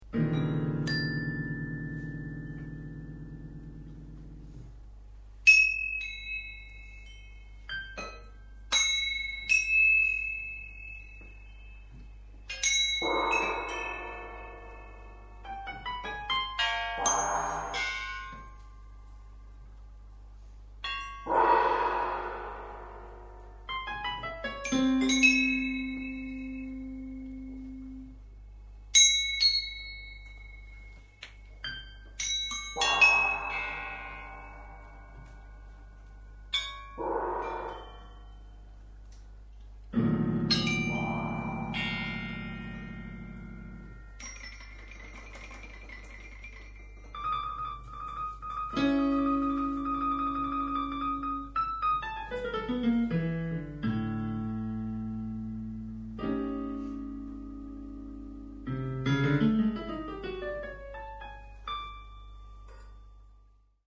Obra realizada en la jornada “Musijugando en vacaciones”
percusión (metales)
piano